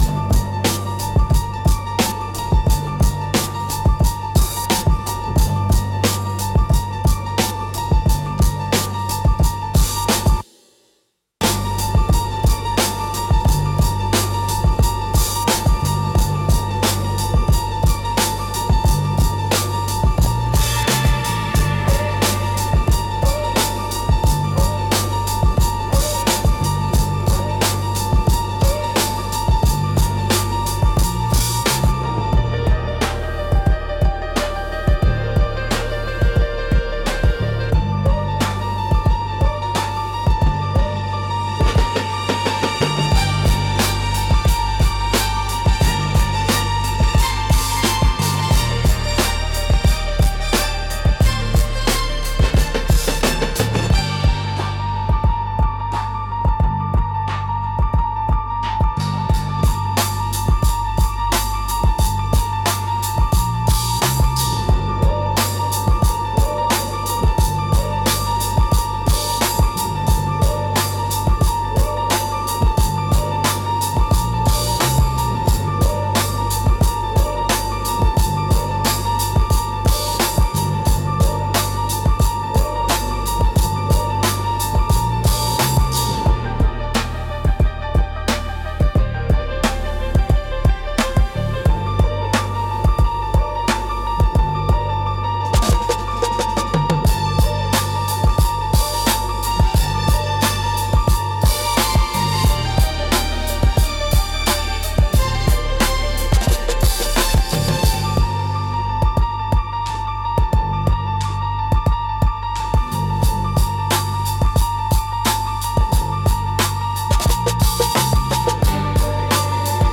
Instrumental - Velvet Curiosity - 3.16 mins